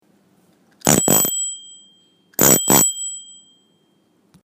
Tokyobell - Crystal Bell - ringeklokke
Lyden af klassisk ringeklokke, med synlige tandhjul, der drejer rundt når du ringer med klokken.